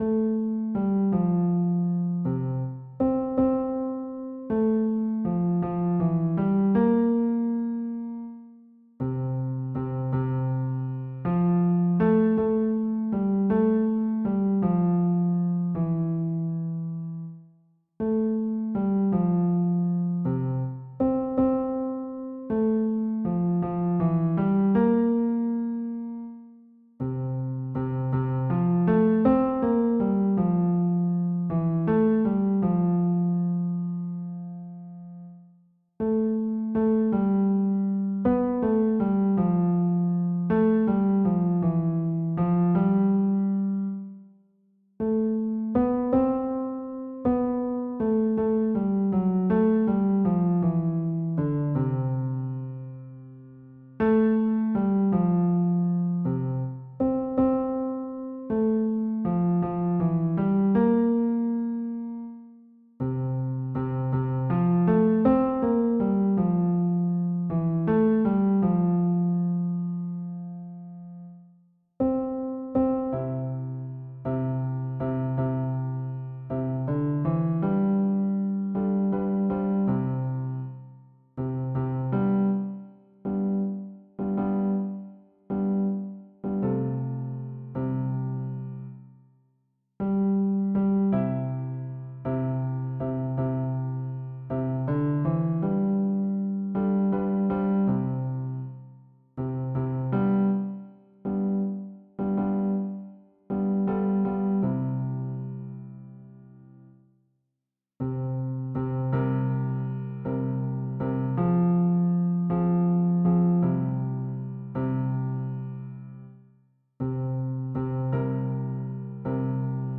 R�p�tition de la pi�ce musicale N� 606